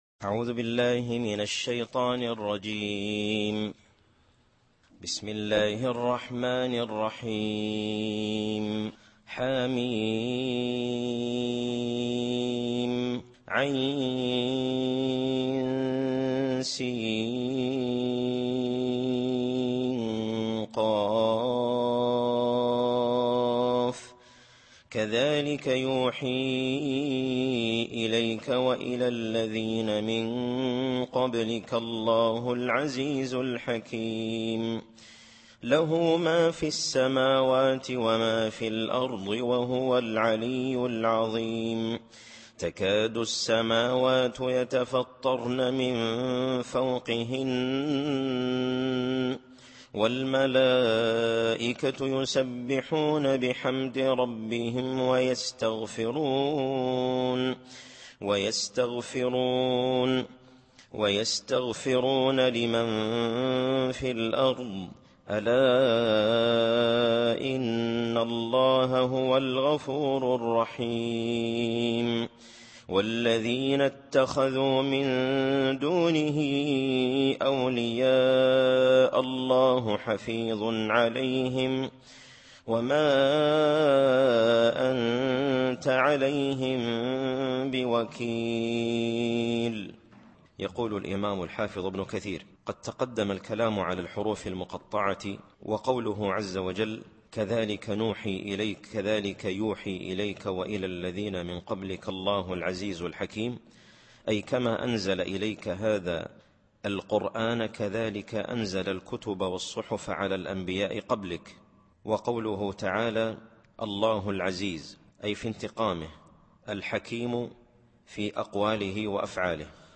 التفسير الصوتي [الشورى / 3]